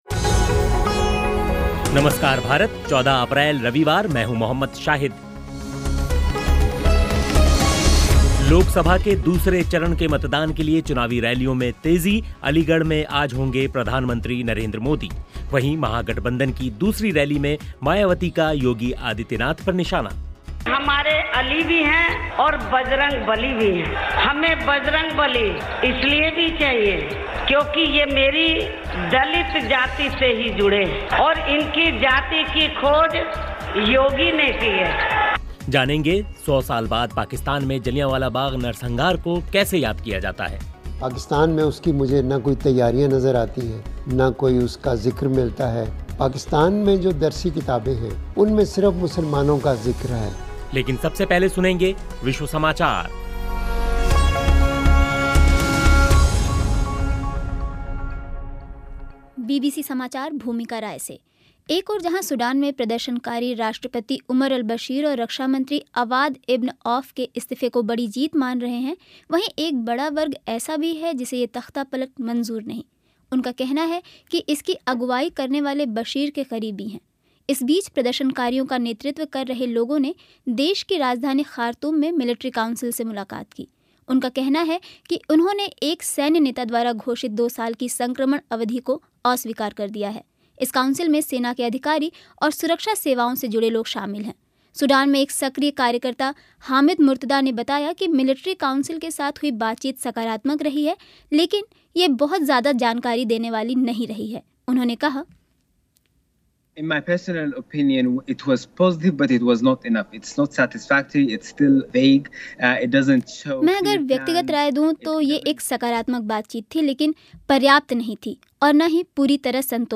अख़बारों की समीक्षा भी होगी लेकिन सबसे पहले विश्व समाचार सुनिए.